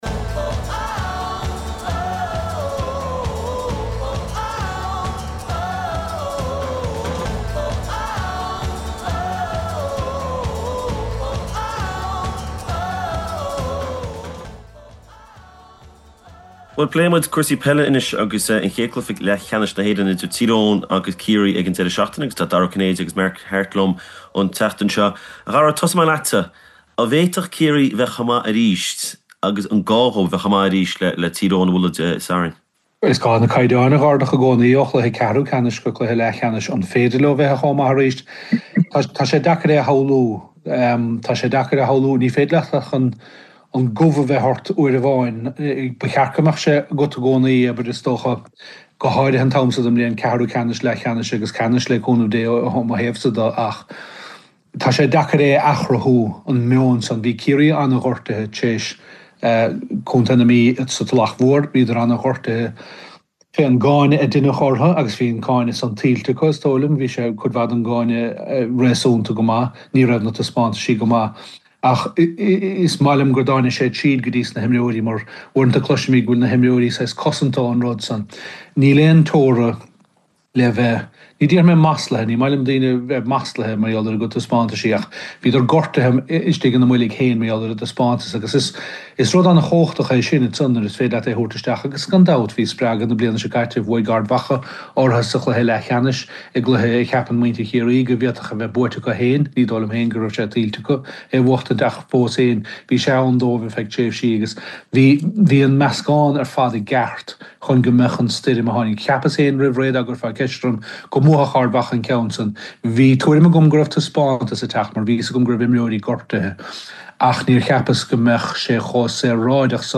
ag caint faoin chluiche idir Ciarraí agus Tír Eoghan.